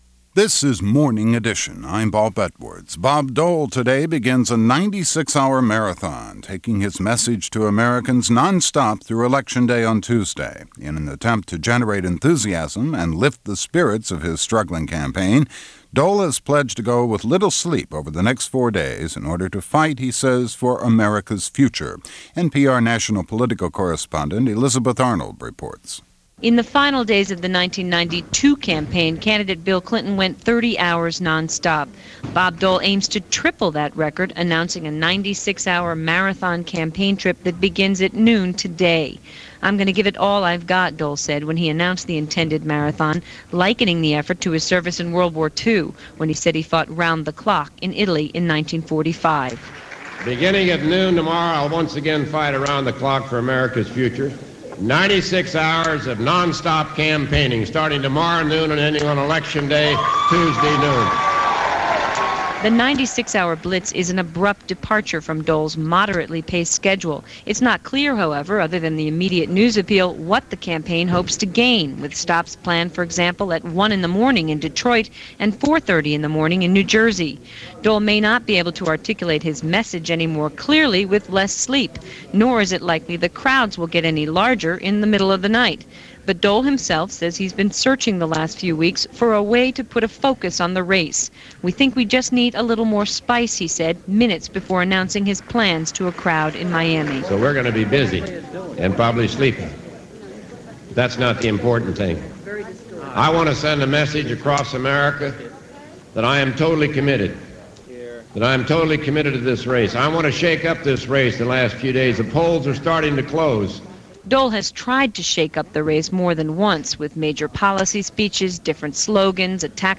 November 1, 1996 - Campaign '96: Down To The Wire - The Sprint - The Promises - The Coattails - news for this day in 1996.